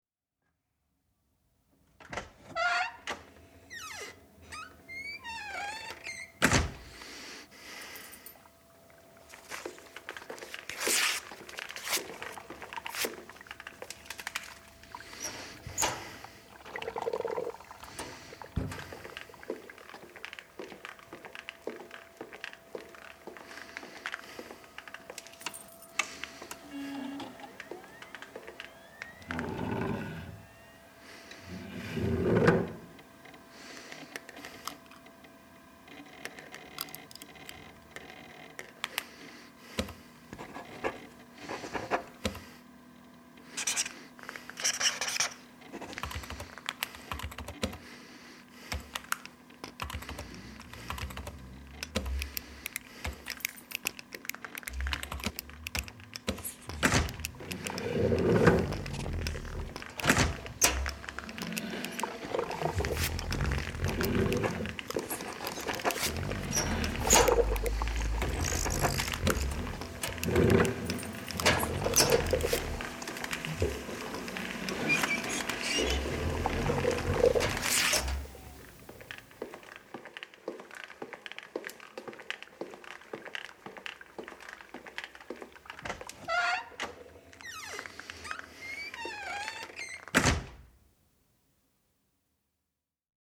example percussive ambience